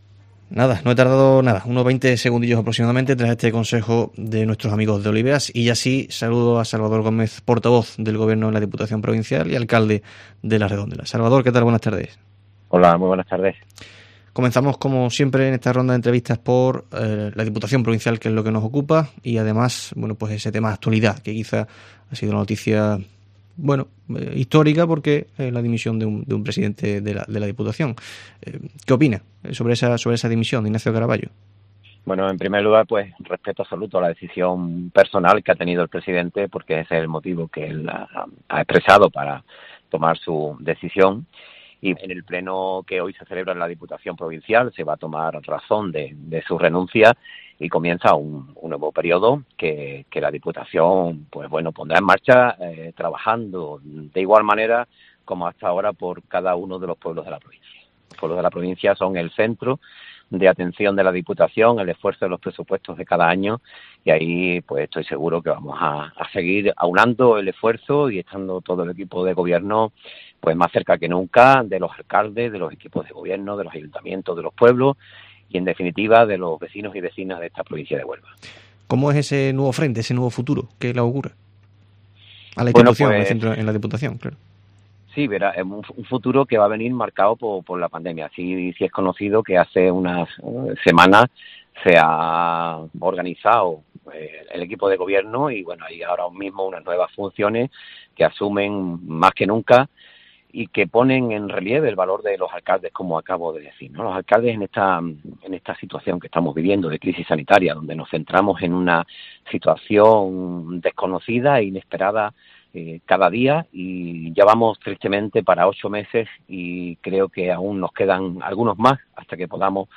Salvador Gómez, portavoz del gobierno en la Diputación de Huelva, ha sido el entrevistado en el Herrera en COPE Huelva de este viernes 6 de noviembre para abordar asuntos actuales.